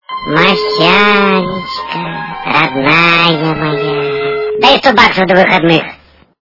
» Звуки » Люди фразы » Масяня - Дай сто баксов